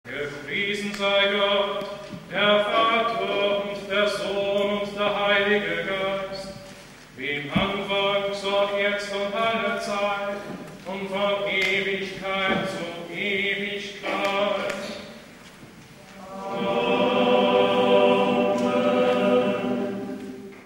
Für den Gebetsrahmen wählte der Gründer wegen seiner Ausdrucksstärke und seiner Erlernbarkeit den Kiewer Choral, wie er der liturgischen Praxis im bayerischen Kloster Niederaltaich (byzantinische Dekanie) zu Grunde liegt, hier mit strenger Terzparallele und Funktionsbass.